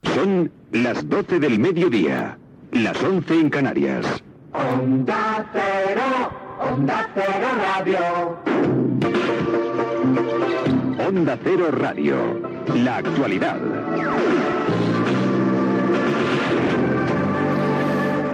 Hora i careta butlletí de les 12:00.
Informatiu